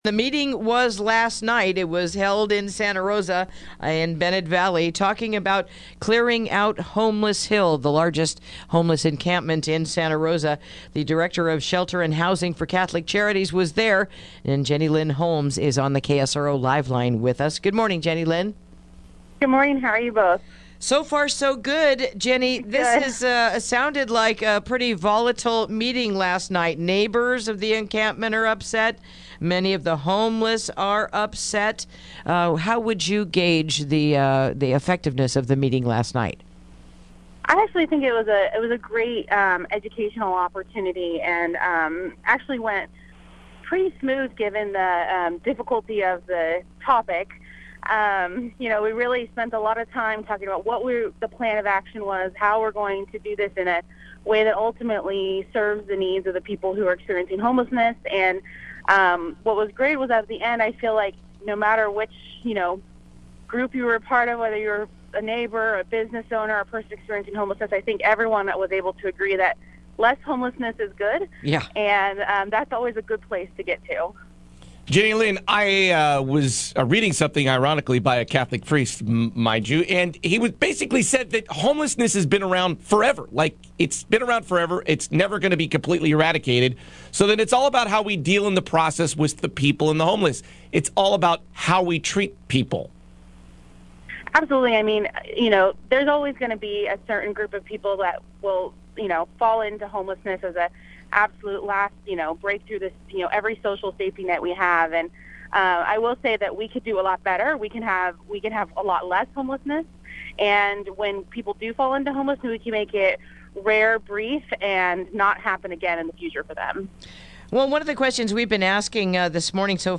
Interview: Meeting Last Night Over Homeless Hill